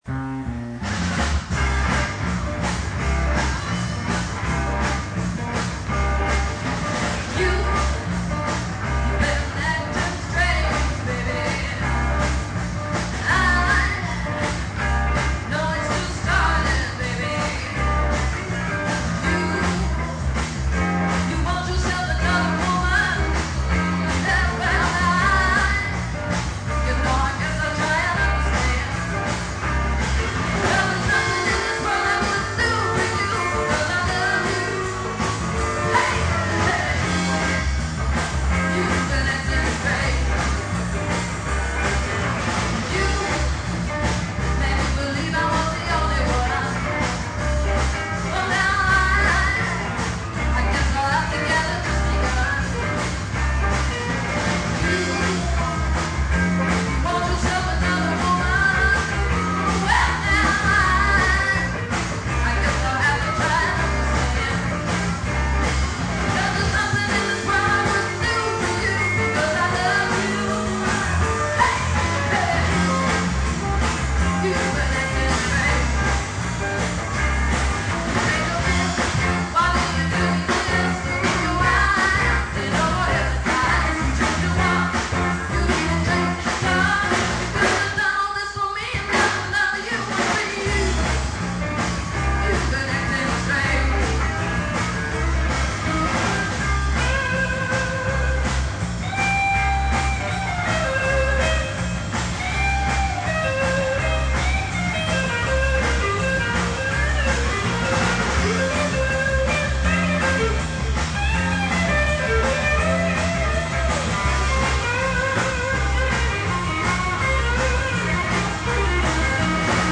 Final Farewell - Cafe L.A.